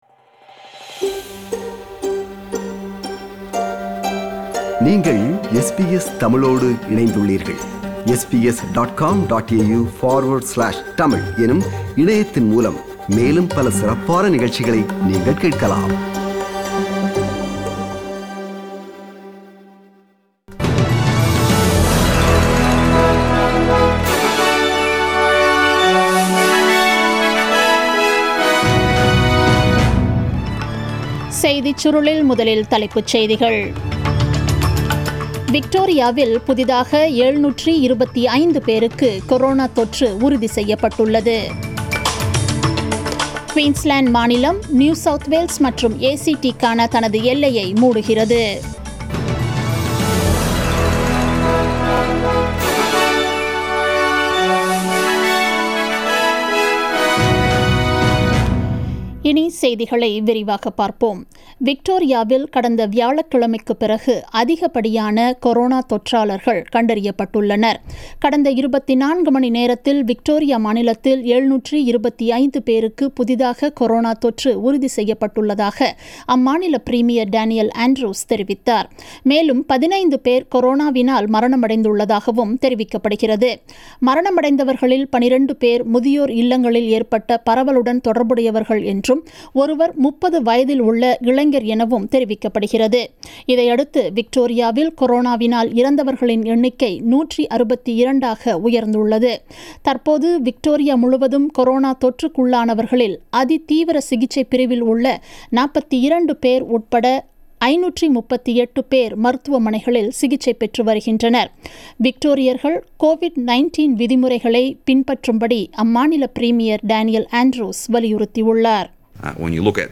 The news bulletin broadcasted on 05 August 2020 at 8pm.